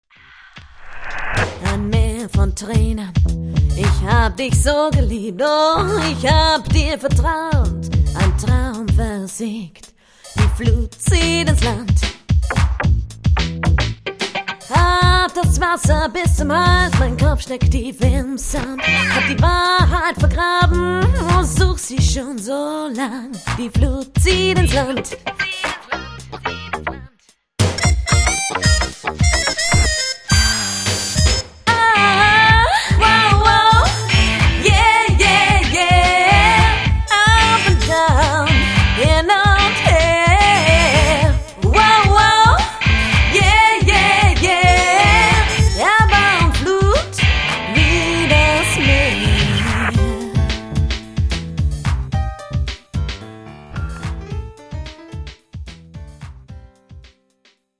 bass
drums/percussion